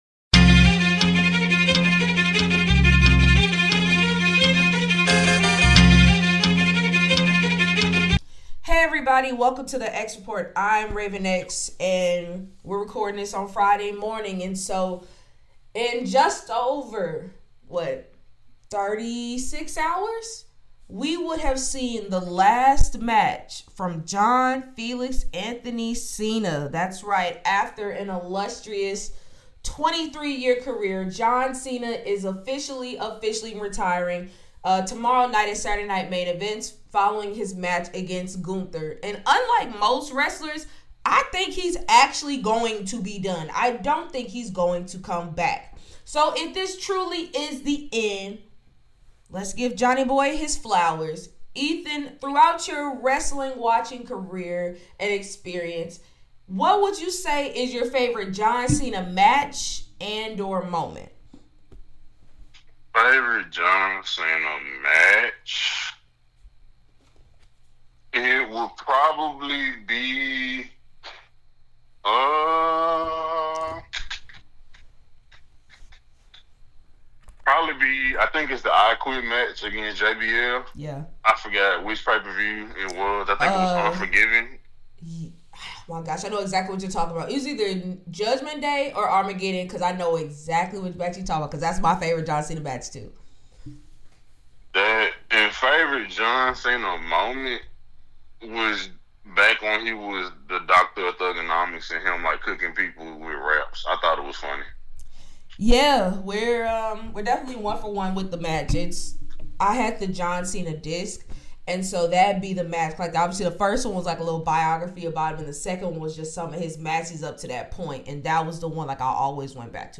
Through witty, entertaining banter and in-depth analysis, The X Report Team takes a look at the NFL, NBA and WWE landscapes, all while staying aware with how they correlate to the issues of social justice!